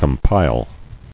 [com·pile]